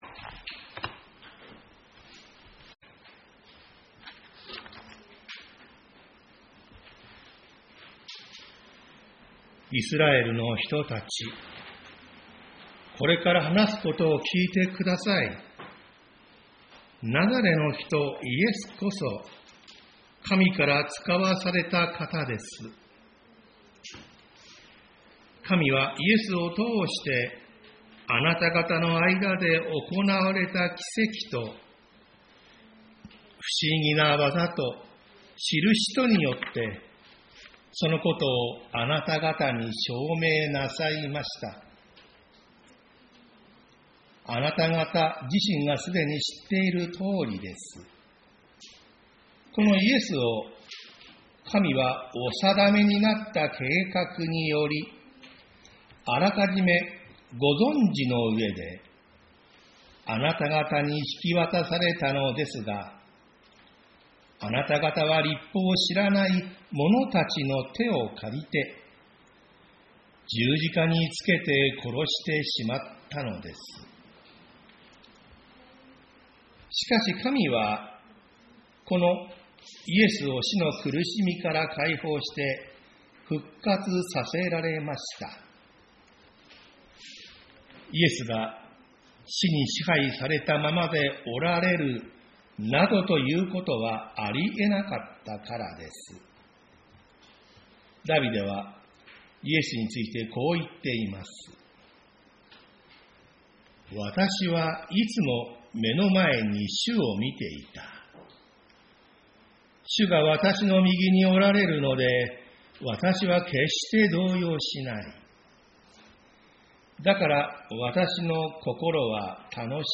私たちは毎週日曜日10時30分から11時45分まで、神様に祈りと感謝をささげる礼拝を開いています。